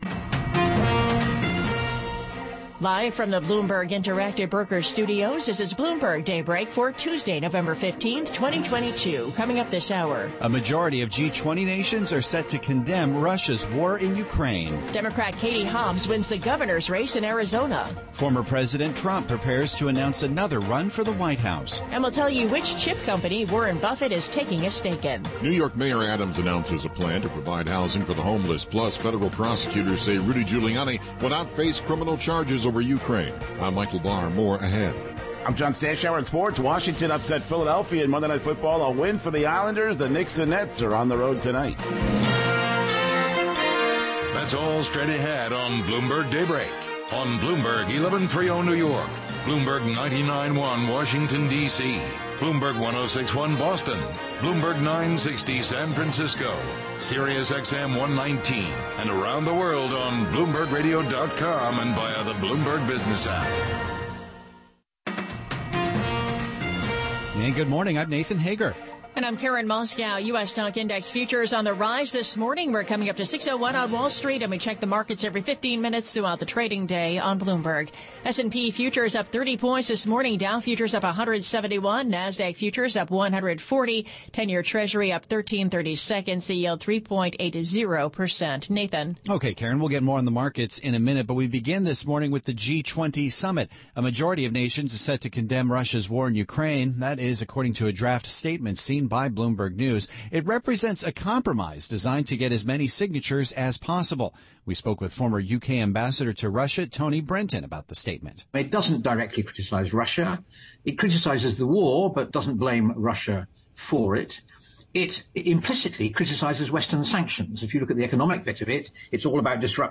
GUESTS: Kenneth Cordele Griffin "Ken" CEO/Co-Chief Invsmt Ofcr/Founder Citadel Enterprise Americas LLC taped interview with Ken Griffin from the Bloomberg New Economy Forum in Singapore.